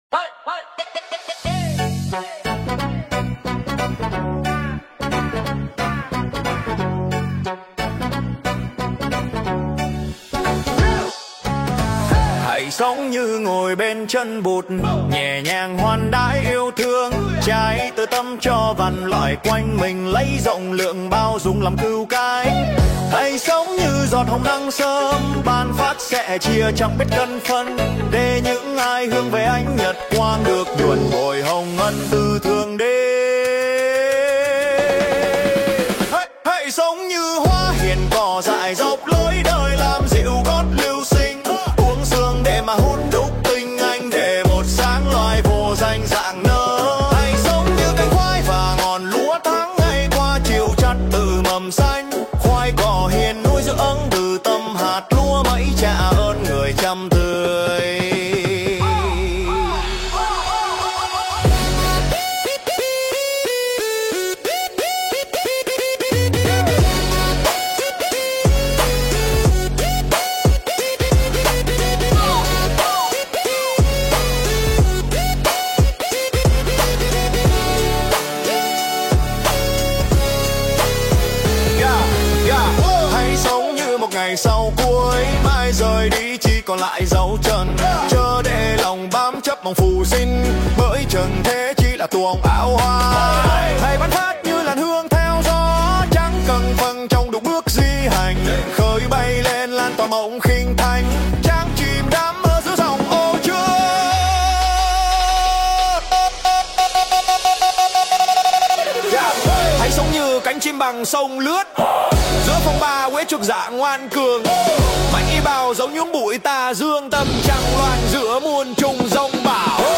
518. Nhạc thiền (số 01) - Vị Lai Pháp